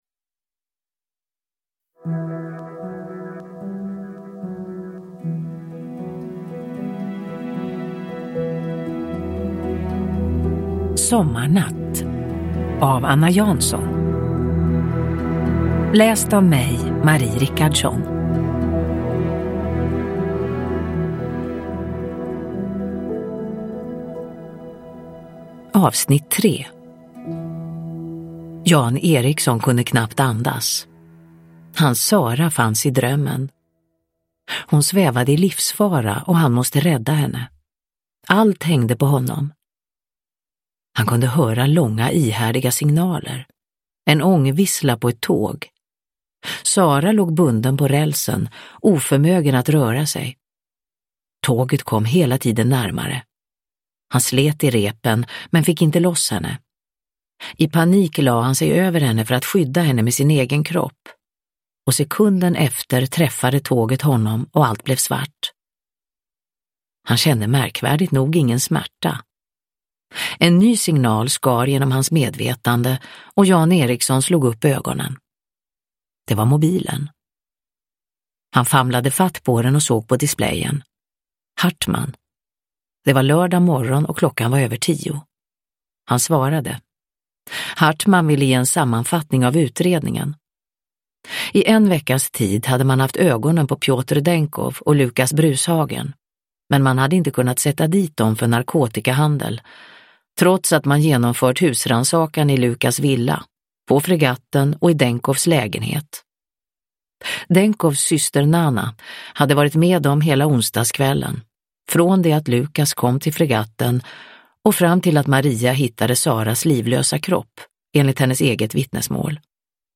Sommarnatt - 3 – Ljudbok – Laddas ner
Uppläsare: Marie Richardson